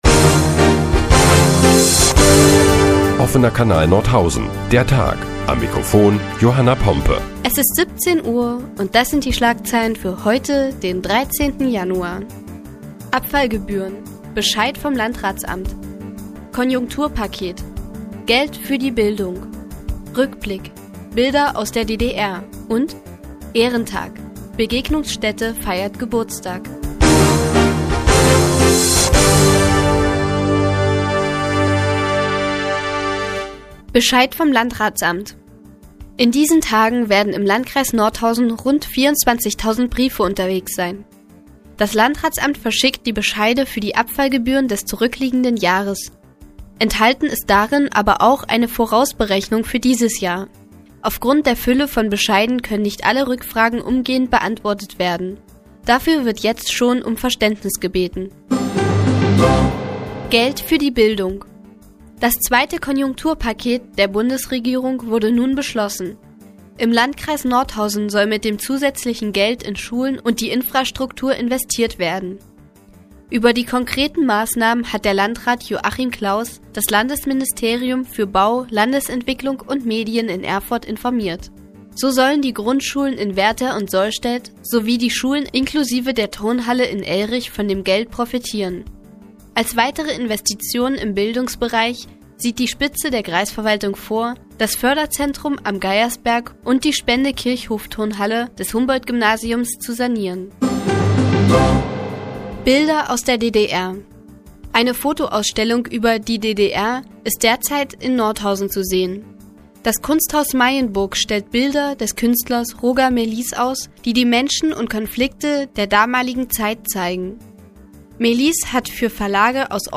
Die tägliche Nachrichtensendung des OKN ist nun auch in der nnz zu hören. Heute geht es unter anderem um Abfallgebühren und das Konjunkturpaket der Bundesregierung.